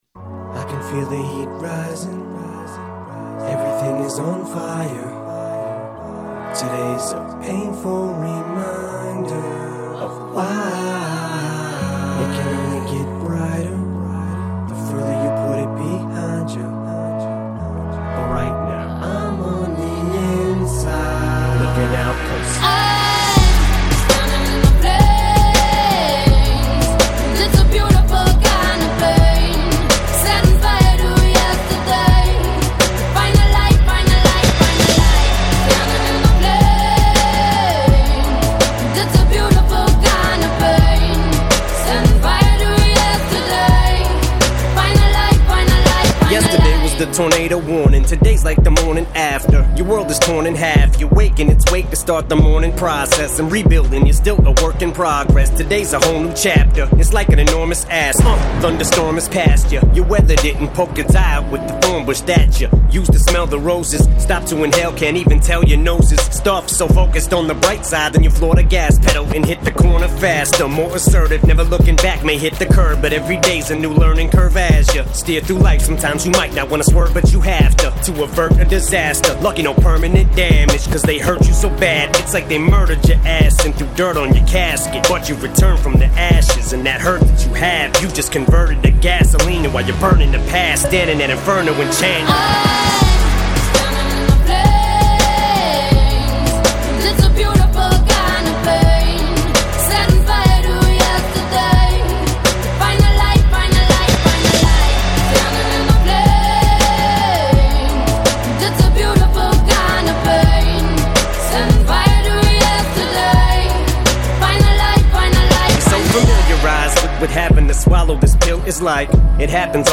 Жанр: foreignrap